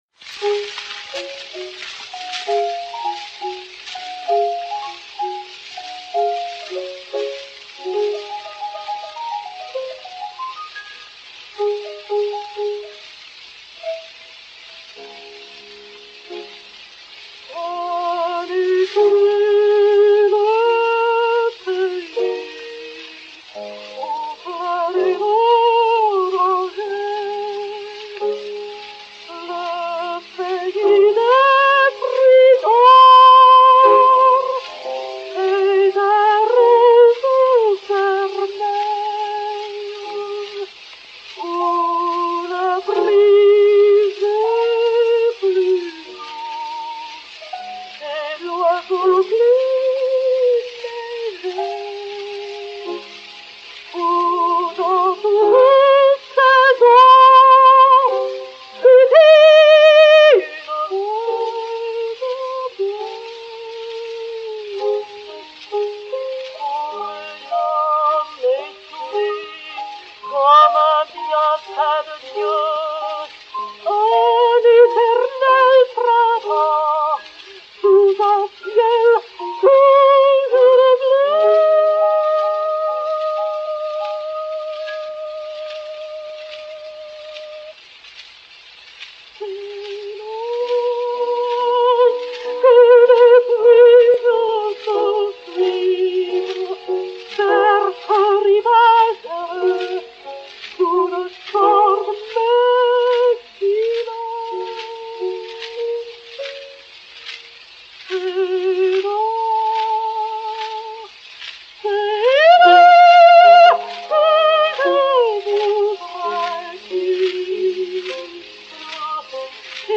au piano
enr. à Londres en juin 1906